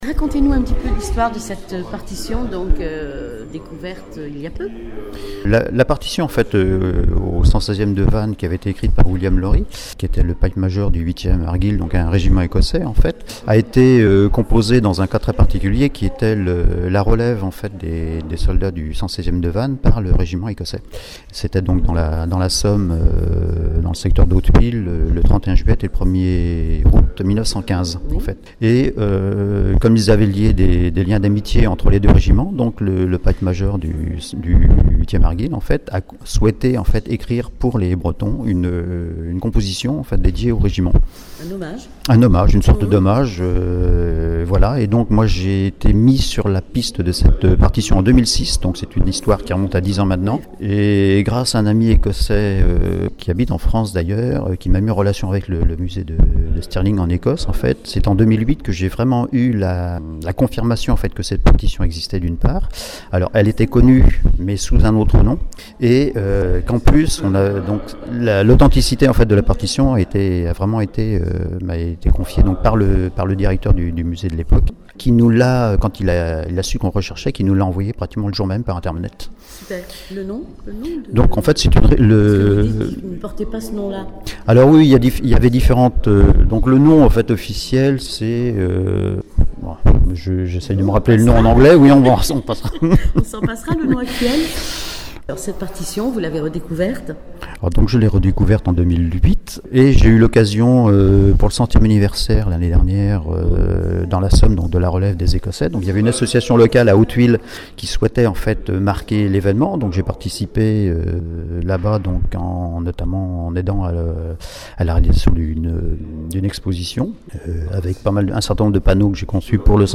Interview de Anne LE HENANFF – Maire Adjointe à la Ville de Vannes